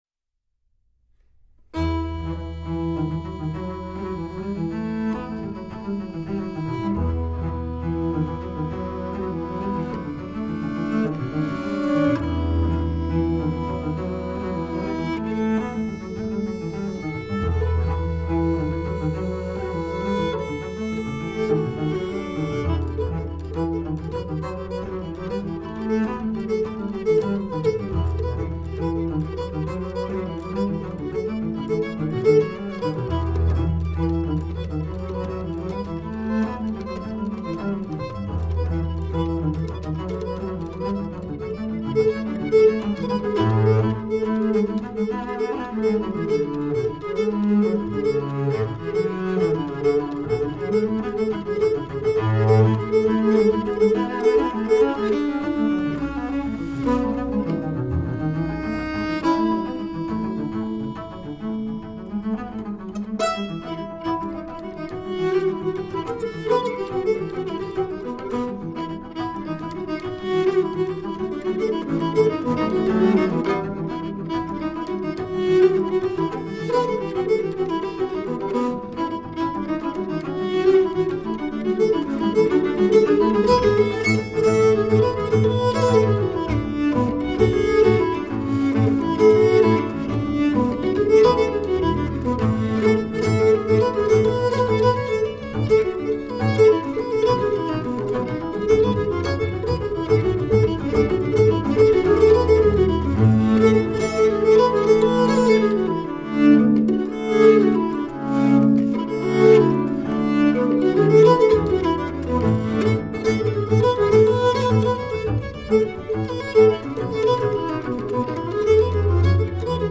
Acoustic string duo
traditional American Old-Time tune